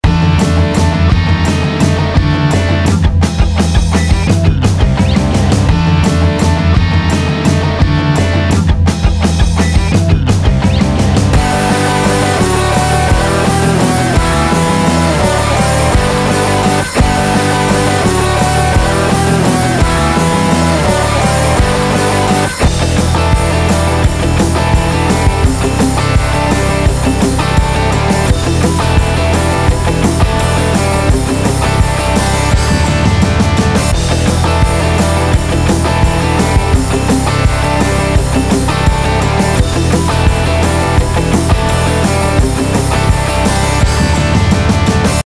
Um Bässe und Höhen HiFi-mässig noch etwas zu betonen, schalte ich das Low Band in den Big-Modus, das High-Band in den Air-Modus.